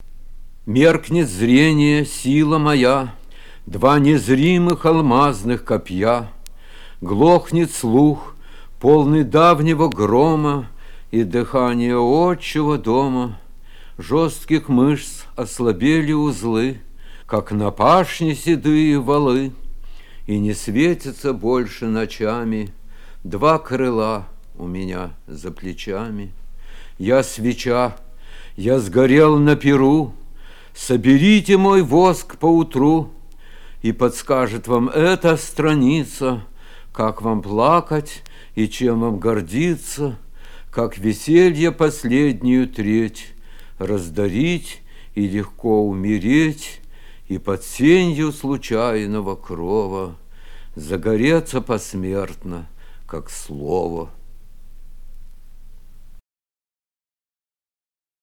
1. «Арсений Тарковский – Меркнет зрение, сила моя (читает автор)» /
arsenij-tarkovskij-merknet-zrenie-sila-moya-chitaet-avtor